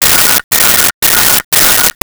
Telephone Ring 03
Telephone Ring 03.wav